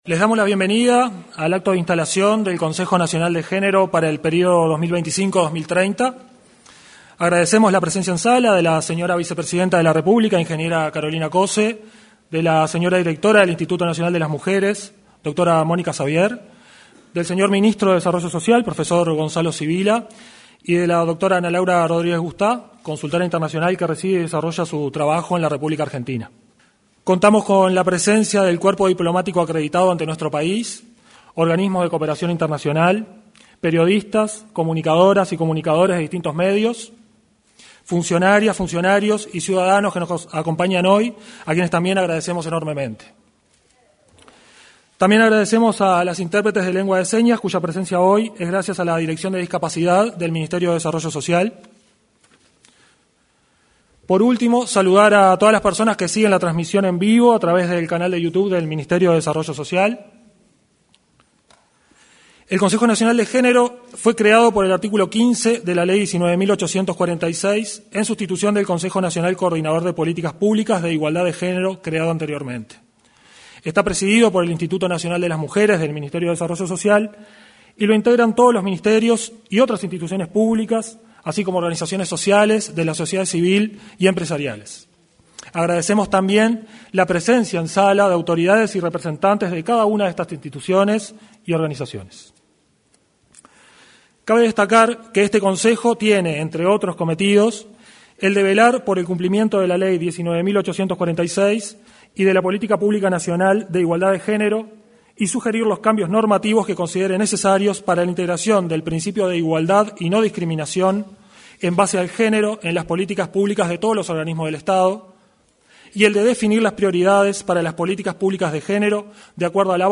En oportunidad de la reinstalación del Consejo Nacional de Género, se expresaron la directora del Instituto Nacional de las Mujeres, Mónica Xavier; el